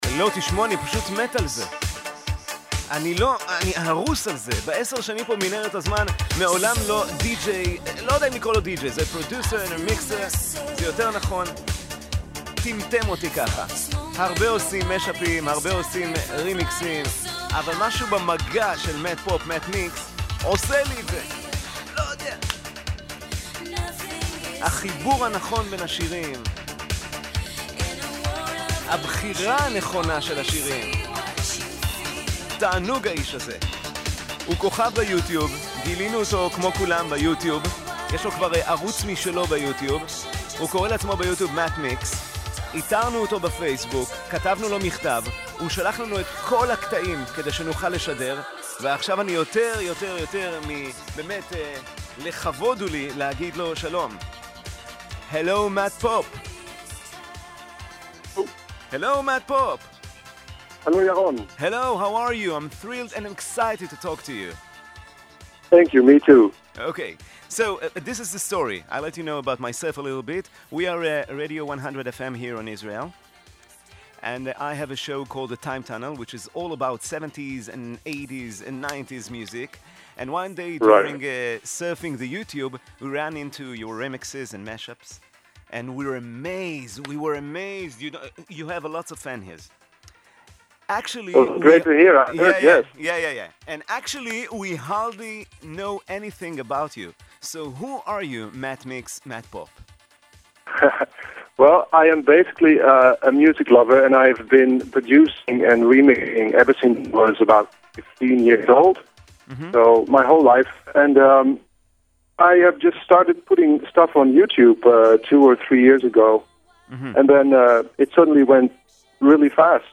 Zó populair zelfs, dat ie door 1 van de populairste radiostations daar geinterviewd is!